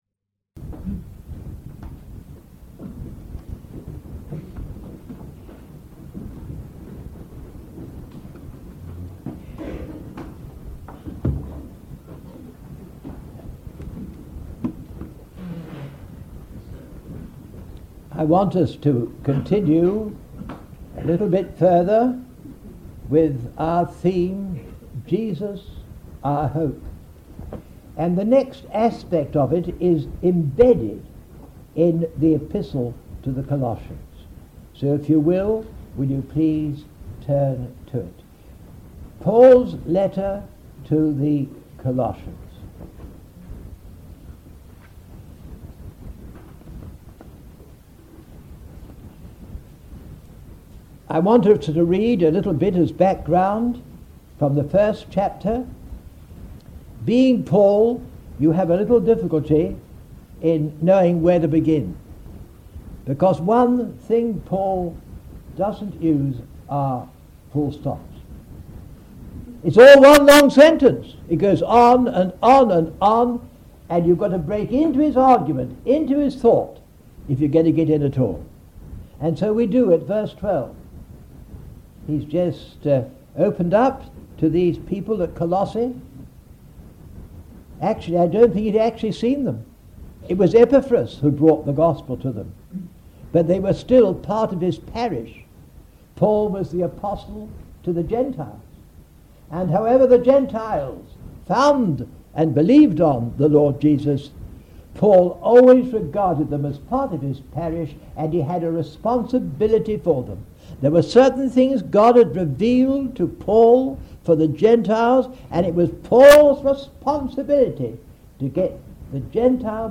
In this sermon, the speaker recounts a story of men who were searching for a simple message of grace.